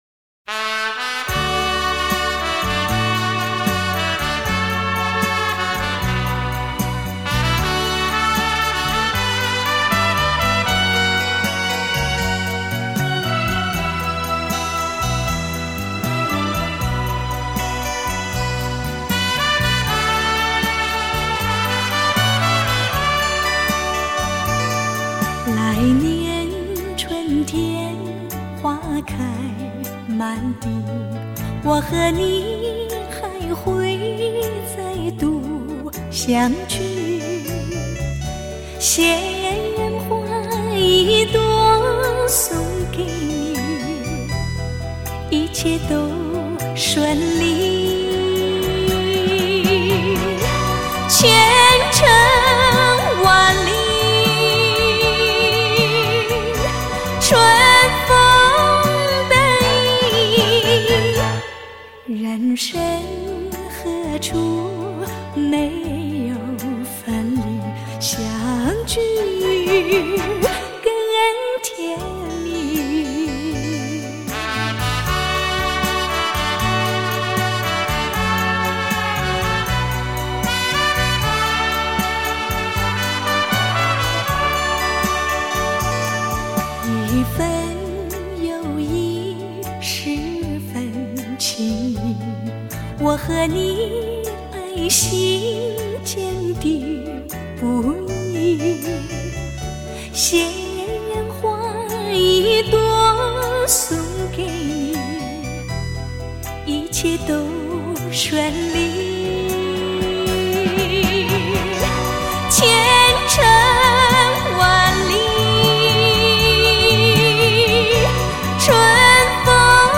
脍炙人口怀念金曲
温馨甜蜜耐人寻味